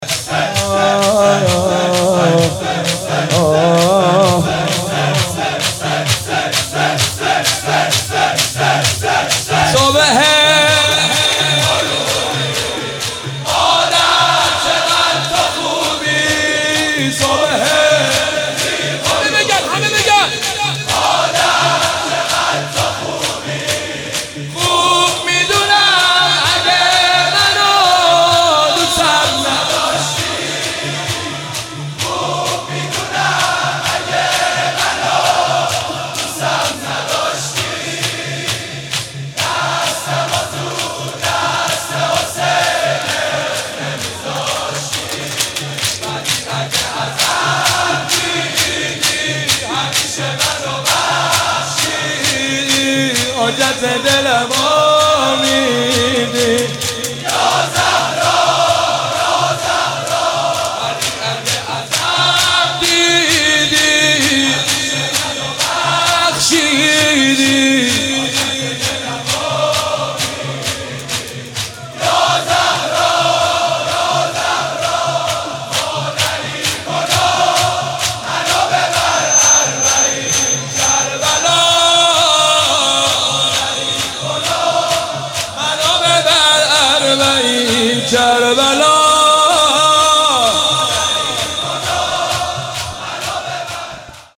مناسبت : شهادت حضرت فاطمه زهرا سلام‌الله‌علیها
قالب : شور